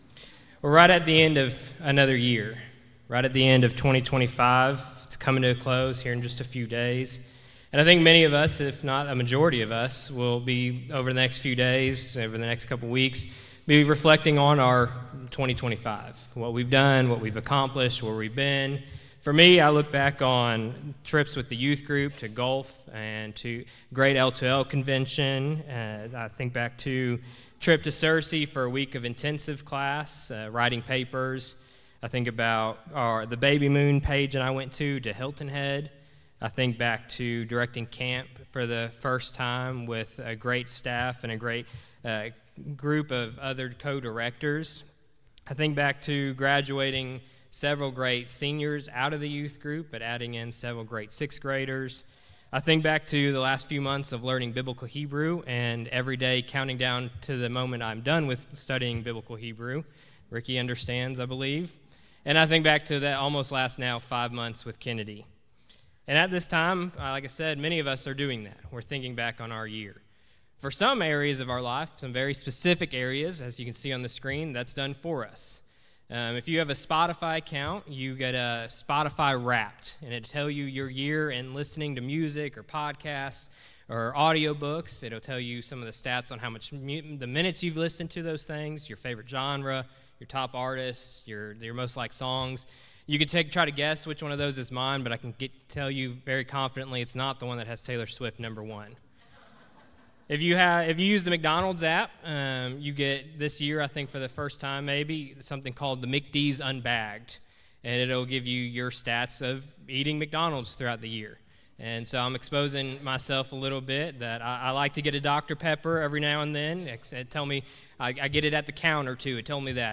Sermons | West Metro Church of Christ